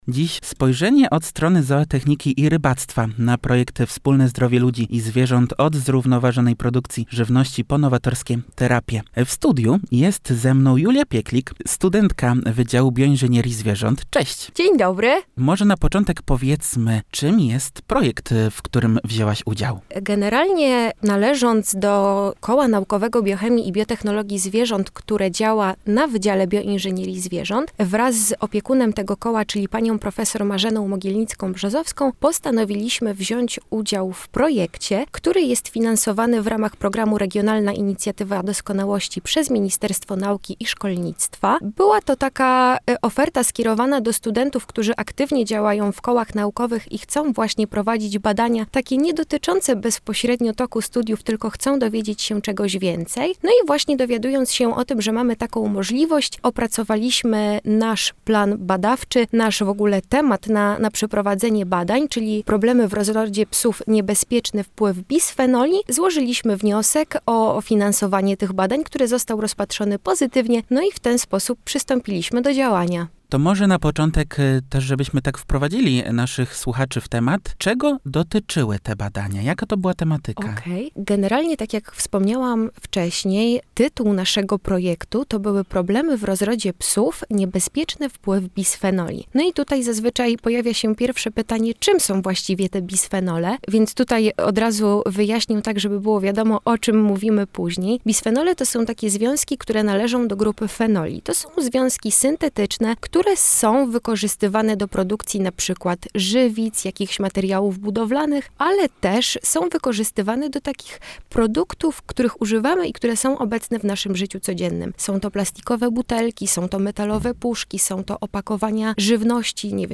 – mówiła w studiu Radia UWM FM